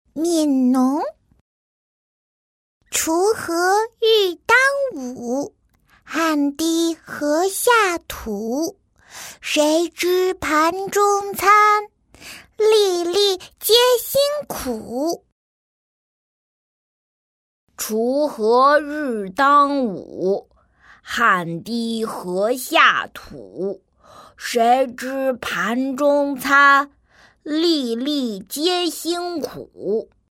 女1-男童女童样音.mp3